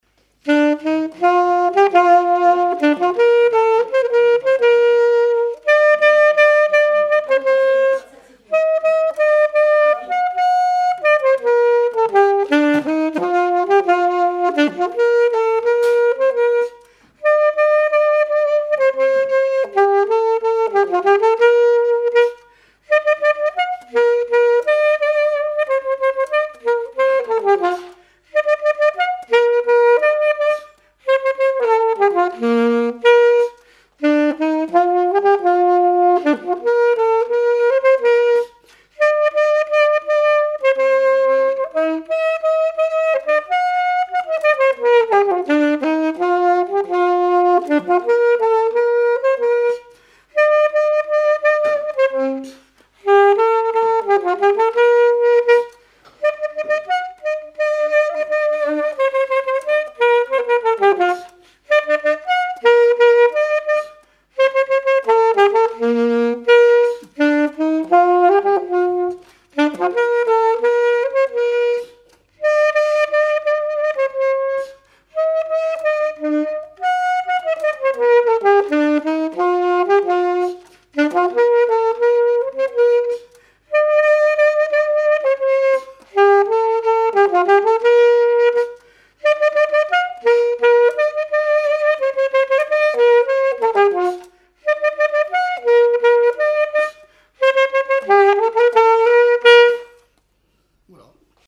saxophone, saxophoniste ; mariage rituel profane
gestuel : à marcher
circonstance : fiançaille, noce
témoignages et instrumentaux
Pièce musicale inédite